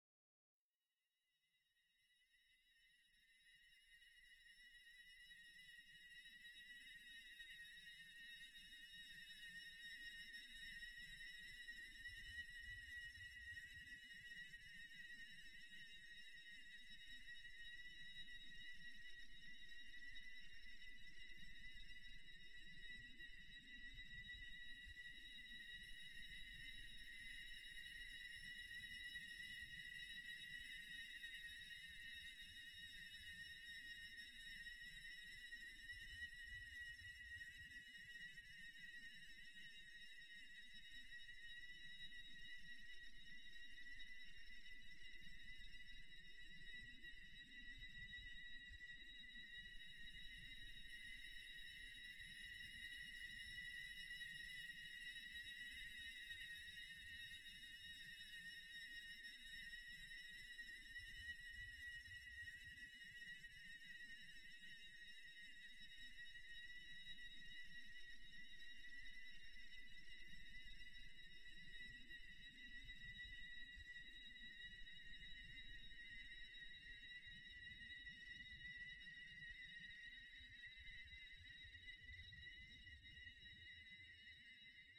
suspense
evil pitch scary suspense tension sound effect free sound royalty free Memes